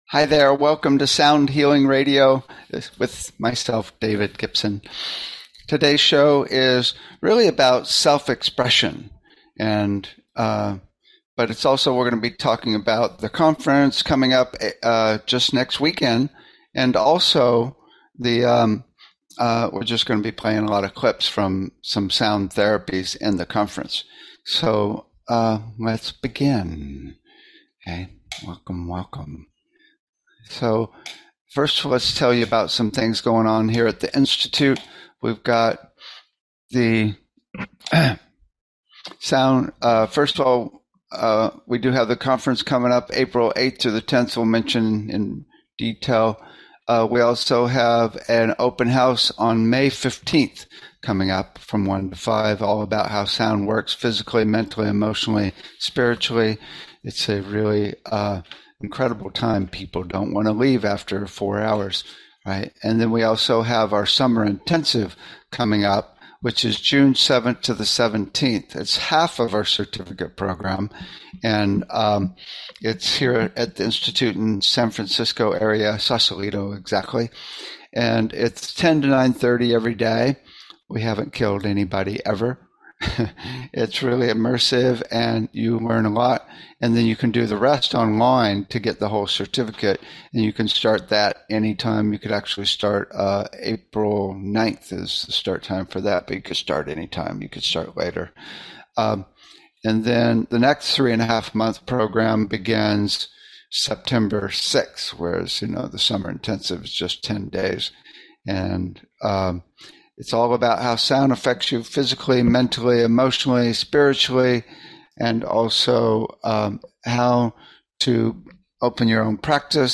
Talk Show Episode, Audio Podcast, Sound Healing and Self Expression, the upcoming conference next weekend, sound therapy clips on , show guests , about Self Expression,upcoming conference next weekend,sound therapy clips, categorized as Earth & Space,Health & Lifestyle,Sound Healing,Kids & Family,Science,Self Help,Society and Culture,Spiritual,Technology